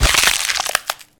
break3.ogg